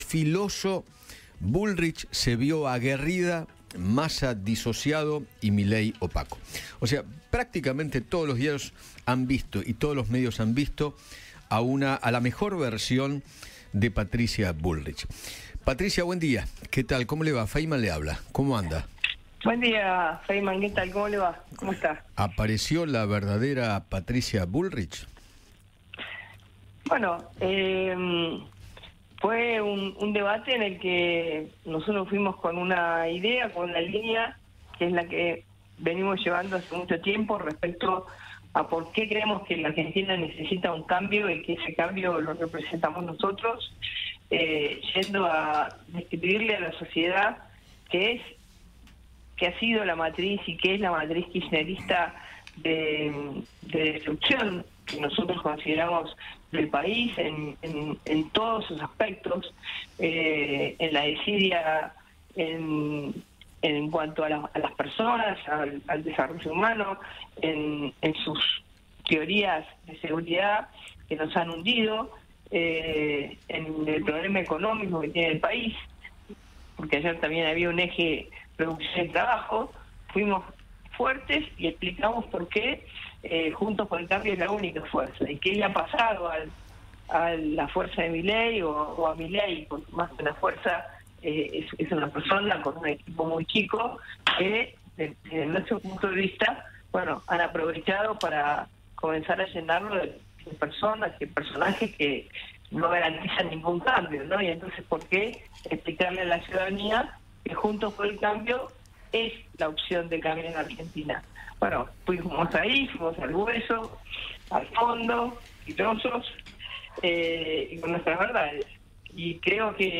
Patricia Bullrich, candidata a presidente, dialogó con Eduardo Feinmann sobre su participación en el último debate previo a las elecciones presidenciales del 22 de octubre.